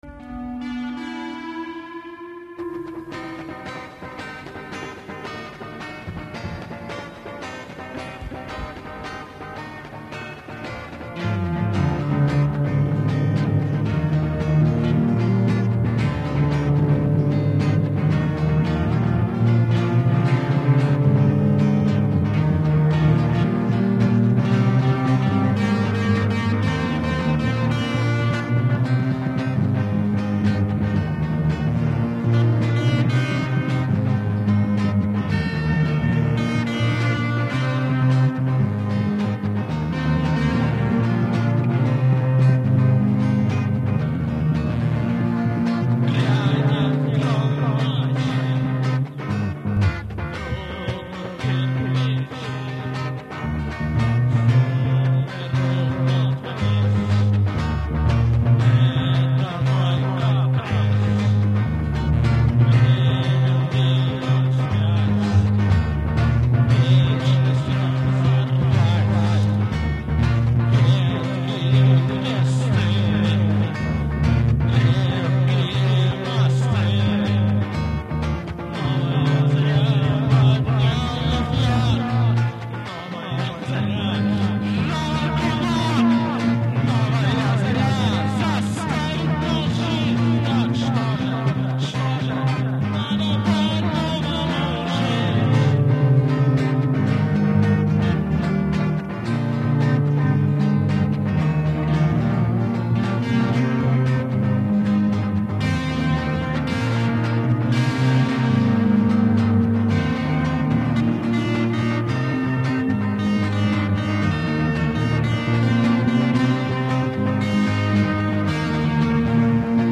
На мой нынешний взгляд нелепо было предполагать, что запись, содержащаяся на кассете, могла вызвать позитивные эмоции жюри, учитывая её отвратительное качество.